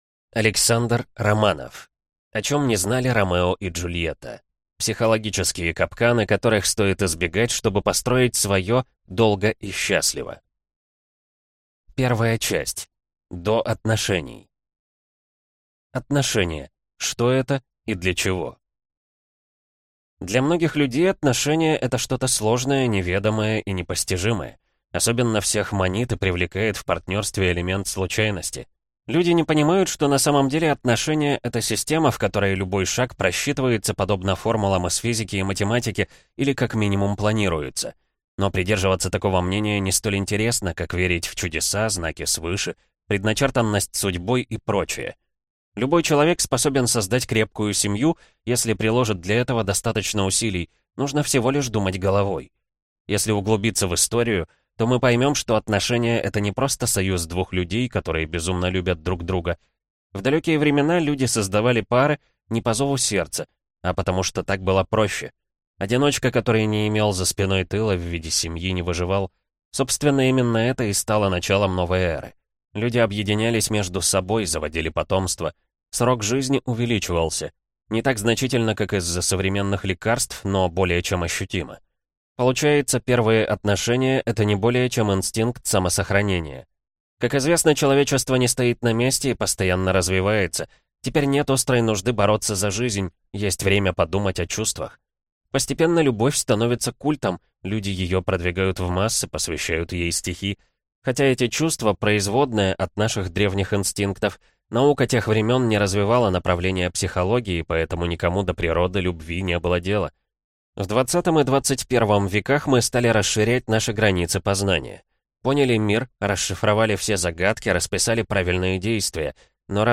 Аудиокнига О чем не знали Ромео и Джульетта. Психологические капканы, которых стоит избегать, чтобы построить свое «долго и счастливо» | Библиотека аудиокниг